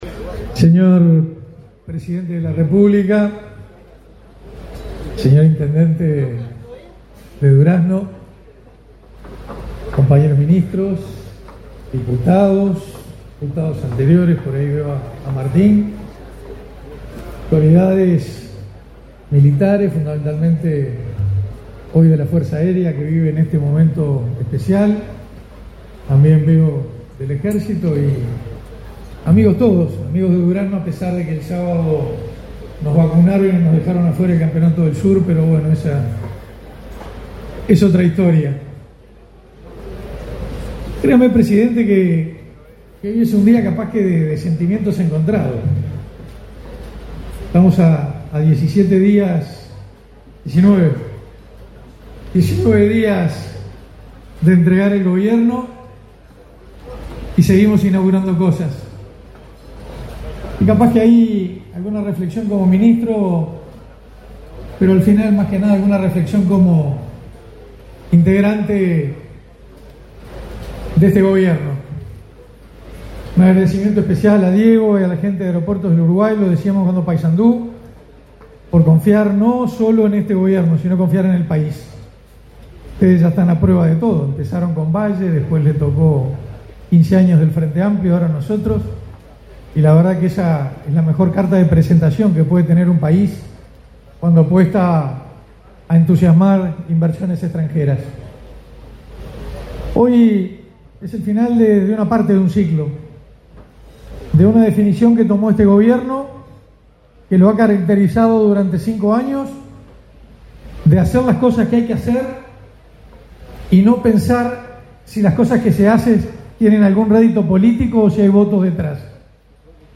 Palabras del ministro de Defensa Nacional, Armando Castaingdebat
Palabras del ministro de Defensa Nacional, Armando Castaingdebat 10/02/2025 Compartir Facebook X Copiar enlace WhatsApp LinkedIn Este 10 de febrero, Aeropuertos Uruguay inauguró el aeropuerto internacional de Durazno, con la presencia del presidente de la República, Luis Lacalle Pou. En el evento, disertó el ministro de Defensa Nacional, Armando Castaingdebat.